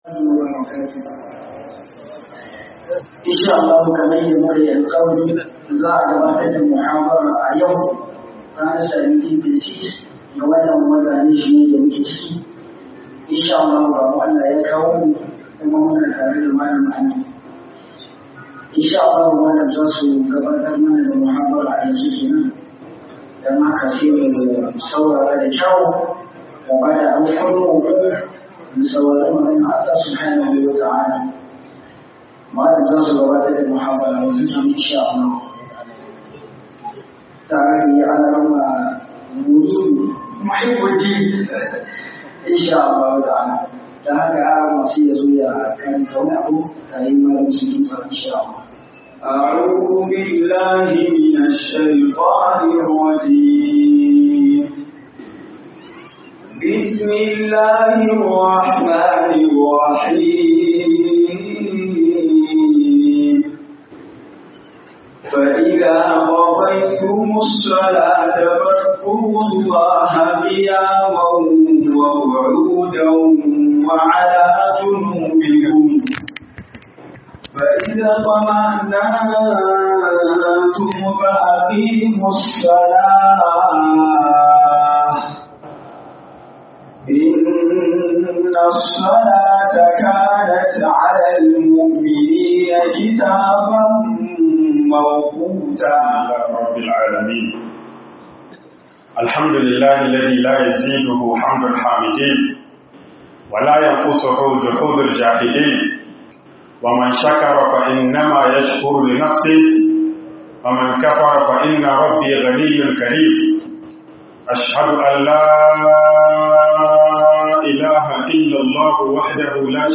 MUHADARA A NIAMEY 01